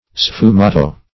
Search Result for " sfumato" : The Collaborative International Dictionary of English v.0.48: Sfumato \Sfu*ma"to\, a. [It.]